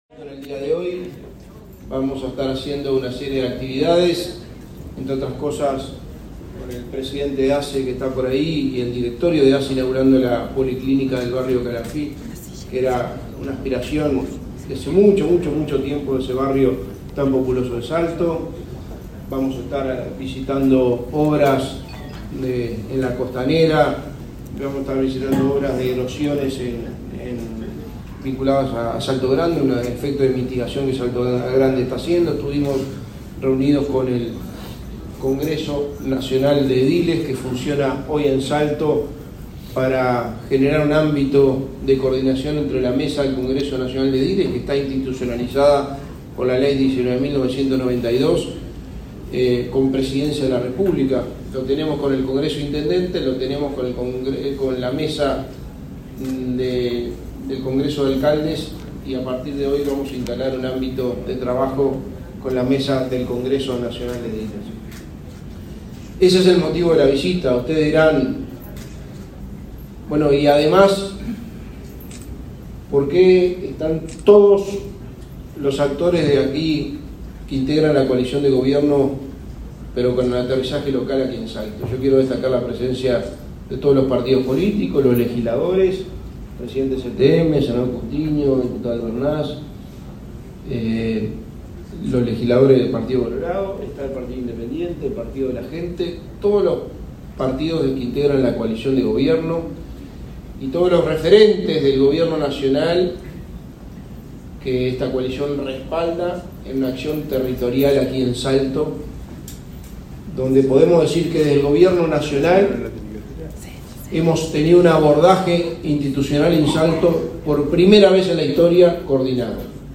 Conferencia del secretario de Presidencia, Álvaro Delgado
Conferencia del secretario de Presidencia, Álvaro Delgado 29/04/2023 Compartir Facebook X Copiar enlace WhatsApp LinkedIn En el marco de una gira por el departamento de Salto, el secretario de la Presidencia, Álvaro Delgado, se expresó en una conferencia de prensa en el hotel Casino de la capital.